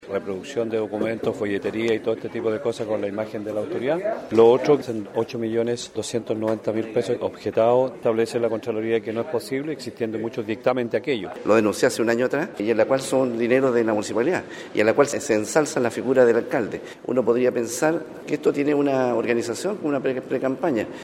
Estas son las declaraciones de ambos ediles.